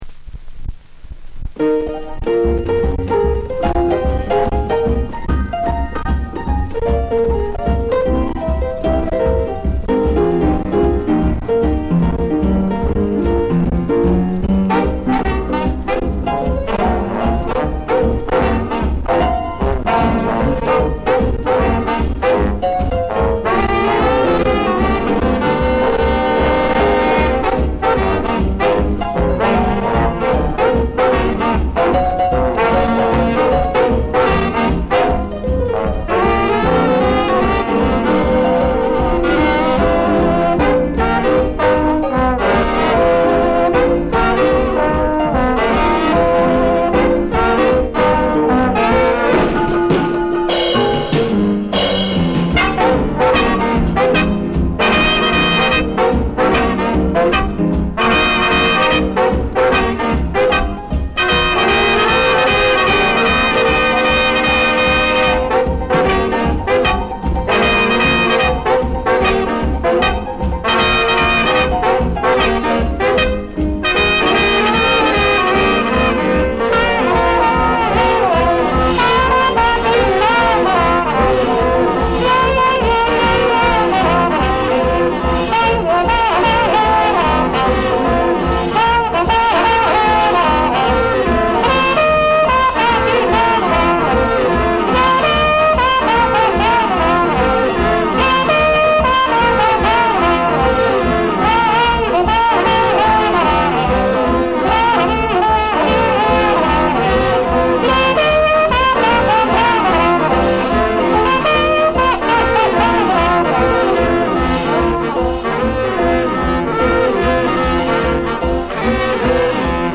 78 rpm
mono
alto sax
trumpet
tenor sax